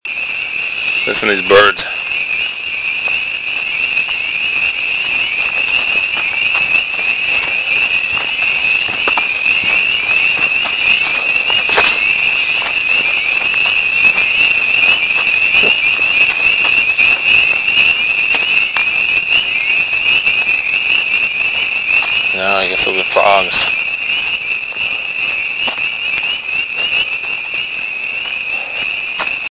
As I neared the inlet of the lake, I heard an amazing noise.  At first I thought they were birds, but I soon realized they were frogs!
frogs.mp3